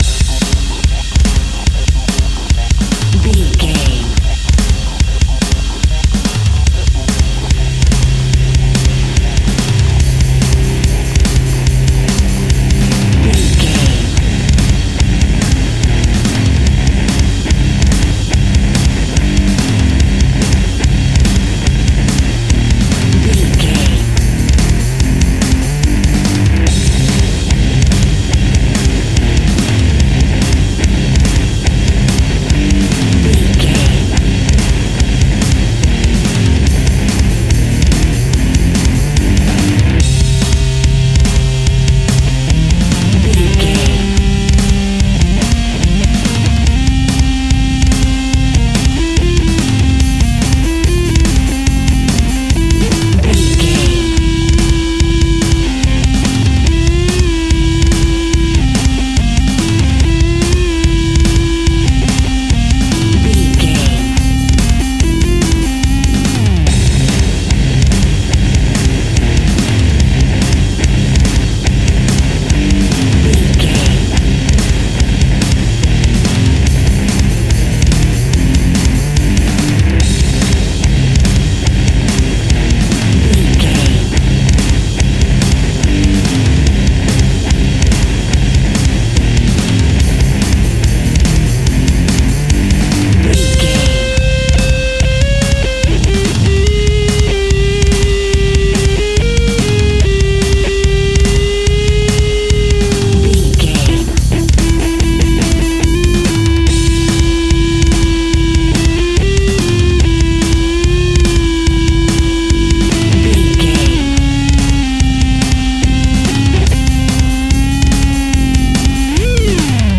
Fast paced
Aeolian/Minor
hard rock
heavy metal
Rock Bass
Rock Drums
heavy drums
distorted guitars
hammond organ